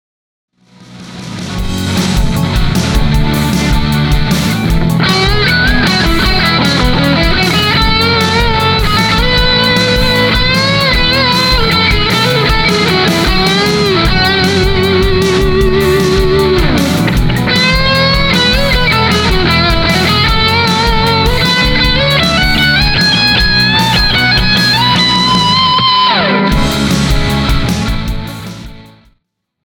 For this, I had the Level at about 3 o’clock and the Drive at 11 o’clock, which slams the front end of my amp, plus adds a healthy amount of distortion. The result is a very touch-sensitve, singing overdrive distortion.
I feels so much richer, and though there’s a LOT of gain with the combination of the pedal and amp overdriving, there is a distinct smoothness to the tone.
mojo_lead.mp3